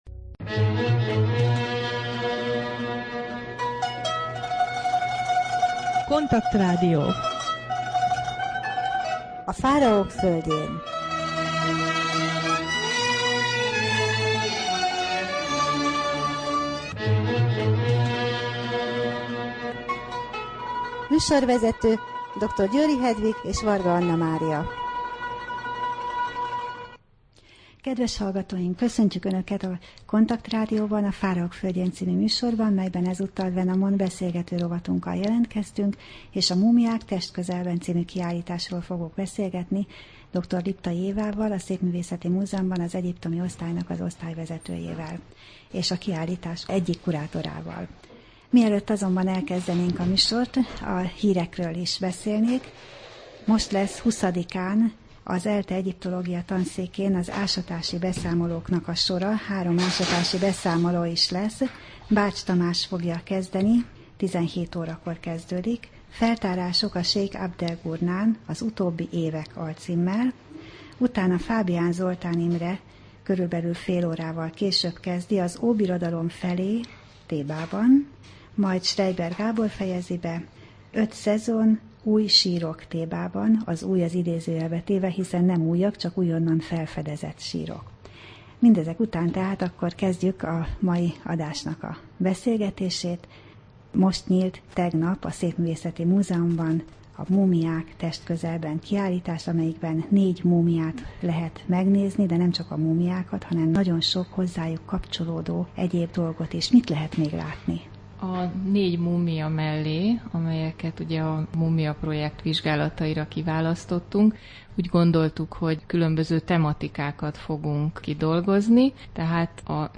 Rádió: Fáraók földjén Adás dátuma: 2011, Június 10 Fáraók földjén Wenamon beszélgető rovat / KONTAKT Rádió (87,6 MHz) 2011 június 10.